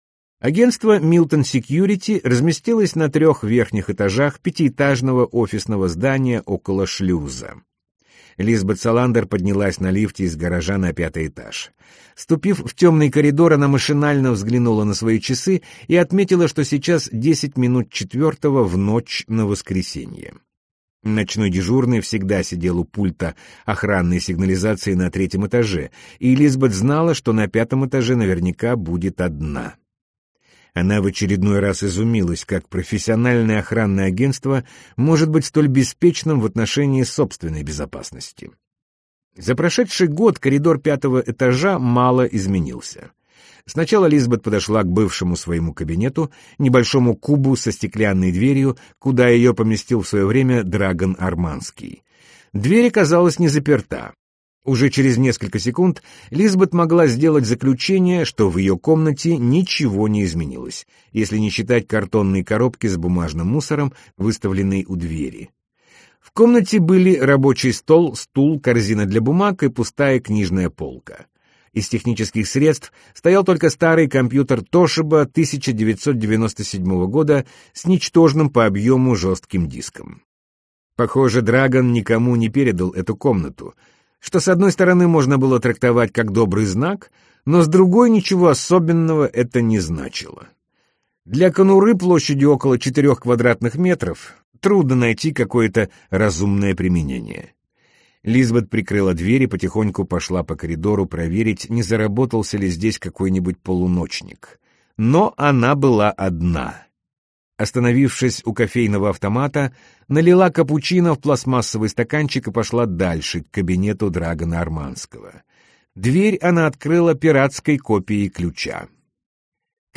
Аудиокнига Девушка, которая играла с огнем - купить, скачать и слушать онлайн | КнигоПоиск